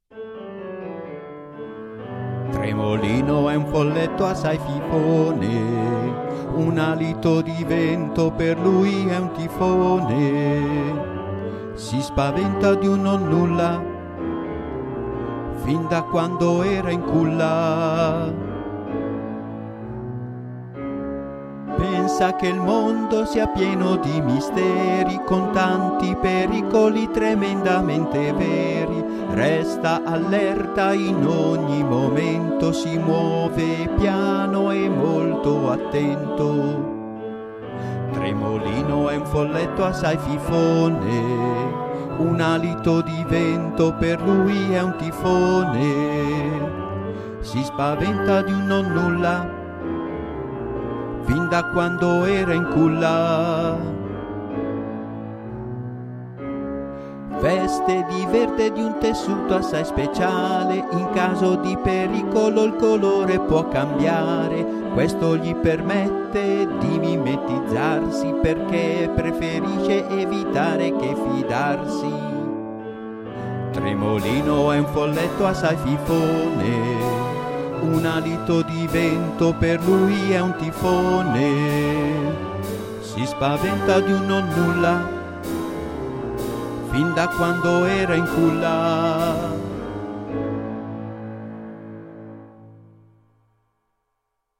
Canzoni dedicate ai sei Folletti scritte musicate e cantate